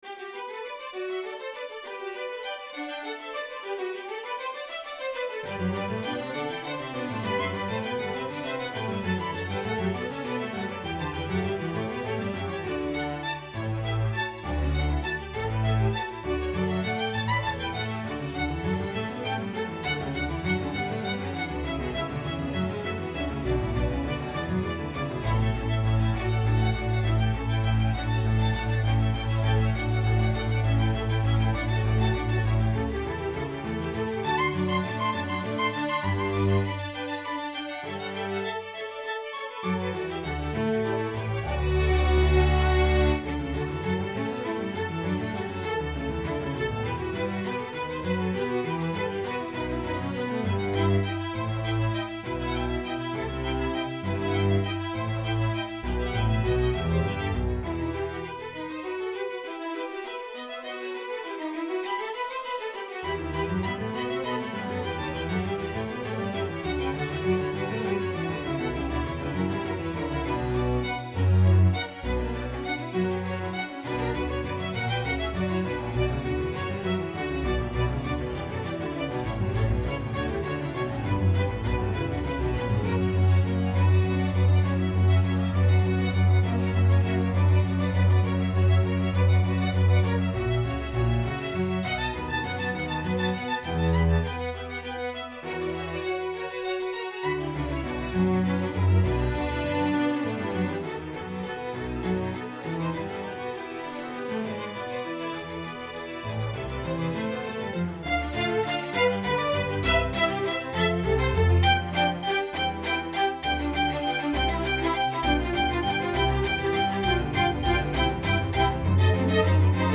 Zur Einführung wurden ein Soft Piano und ein String Ensemble veröffentlicht.